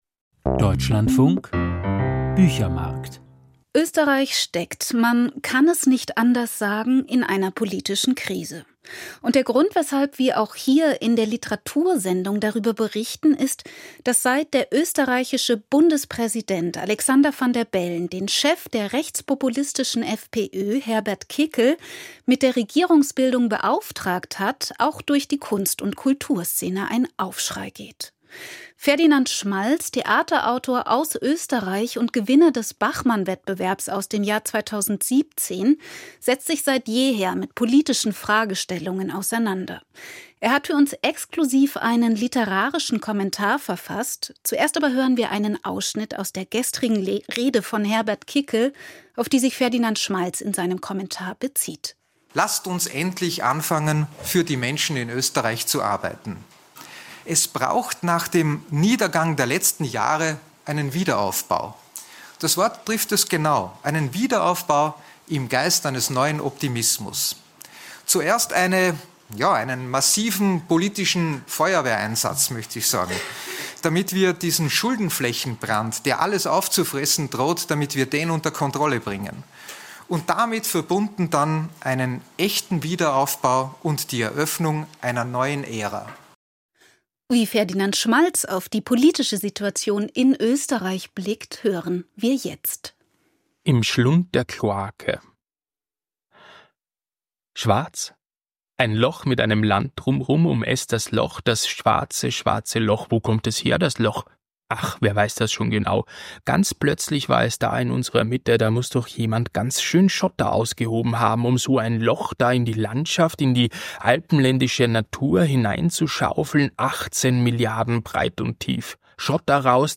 Kommentar zu Österreich